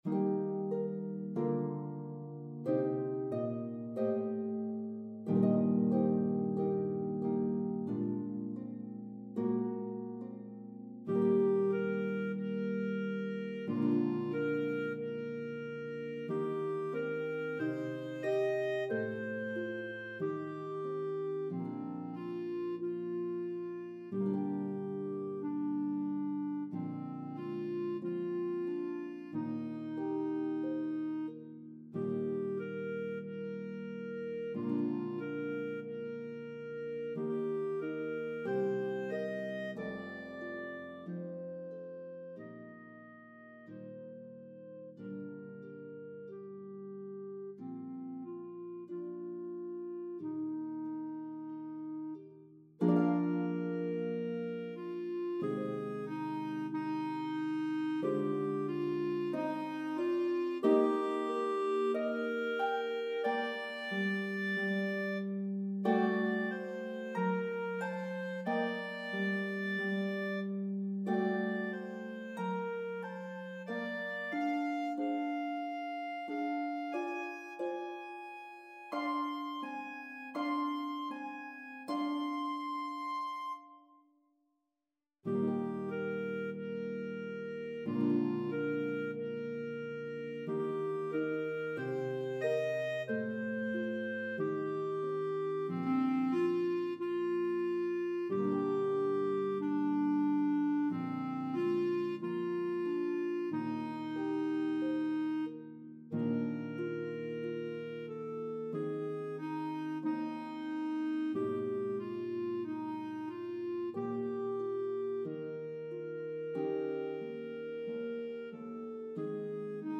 The elegant, famous melody will enchant your audiences!
Harp and Clarinet version